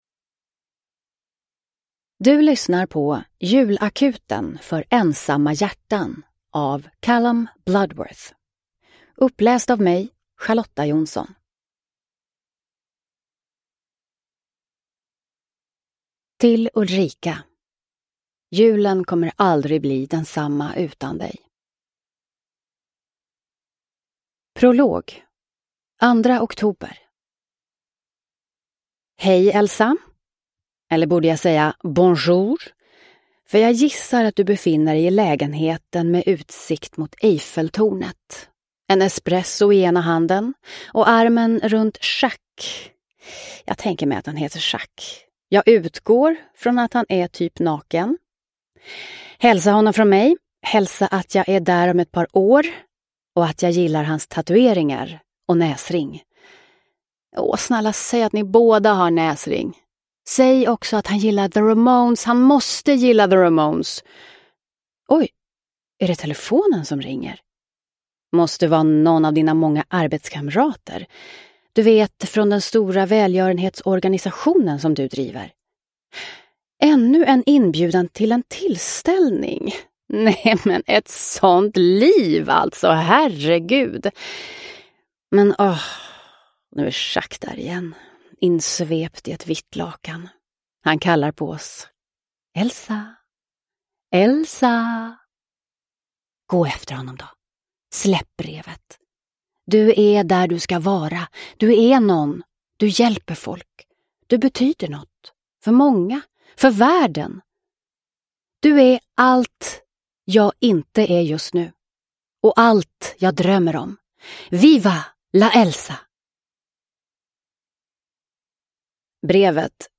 Julakuten för ensamma hjärtan – Ljudbok – Laddas ner